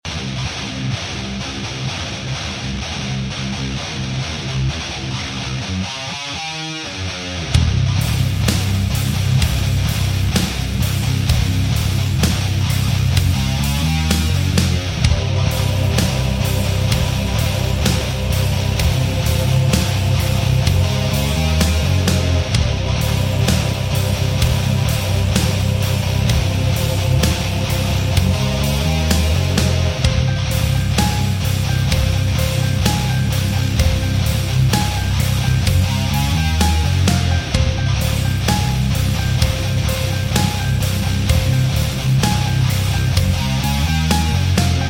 On a scale from 1 to 10, How Heavy is this riff? This is in C tuning Riff number 8 On A Scale From 1 Sound Effects Free Download.